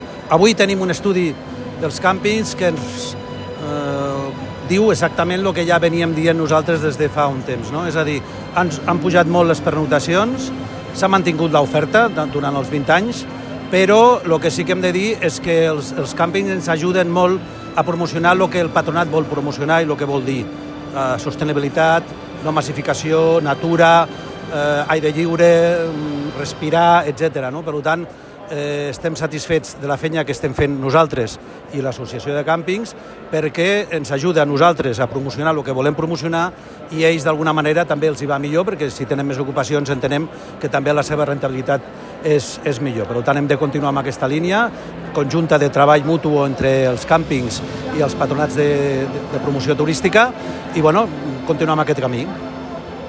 Tall de veu del vicepresident del Patronat de Turisme, Juan Antonio Serrano
Tall-de-veu-del-vicepresident-del-Patronat-de-Turisme-Juan-Antonio-Serrano.m4a